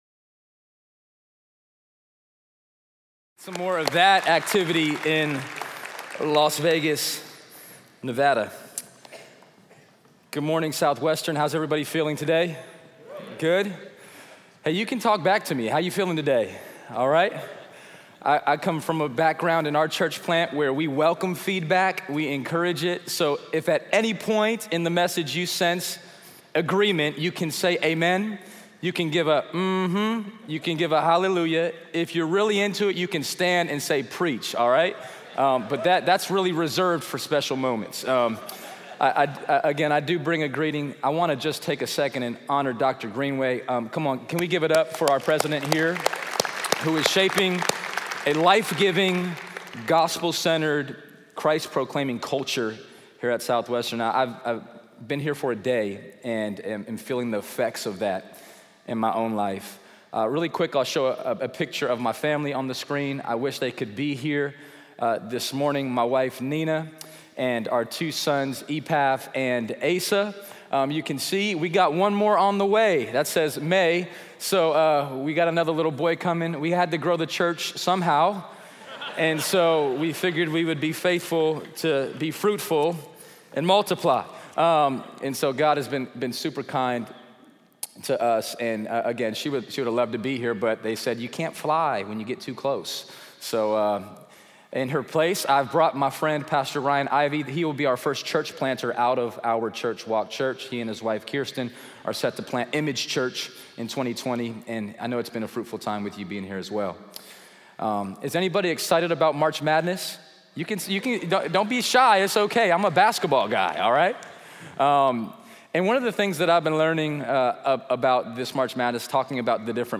in SWBTS Chapel